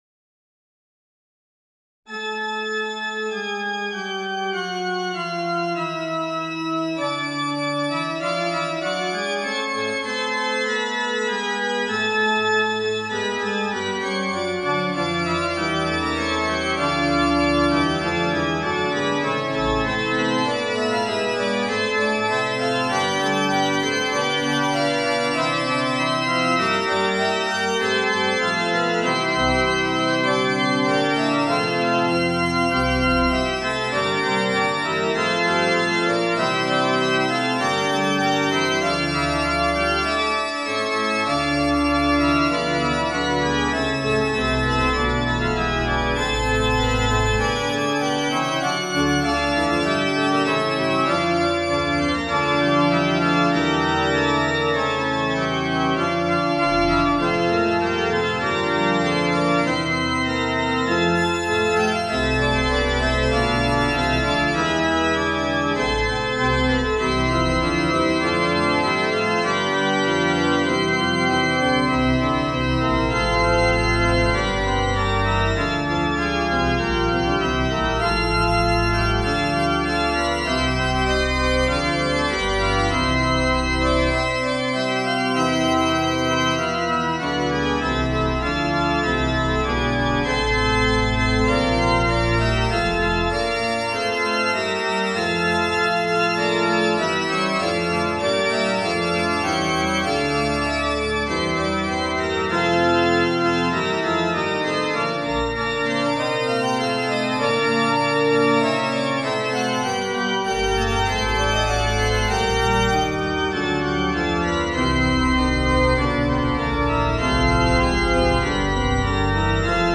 現代の調性的なものとは異り、ルネッサンスのような様式が混ざり合っています。
3度だけの和音が多く,響が充実しない。
半終止や反復進行で中断され,緊張感が持続しない。